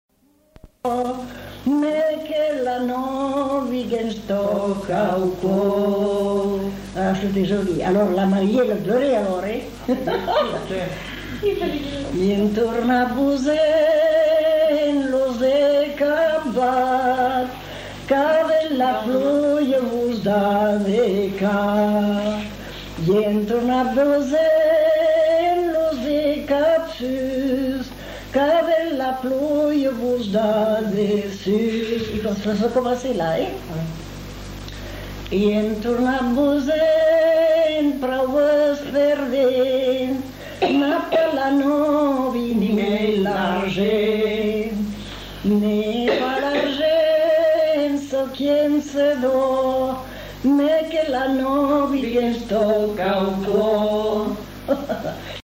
Genre : chant
Effectif : 1
Type de voix : voix de femme
Production du son : chanté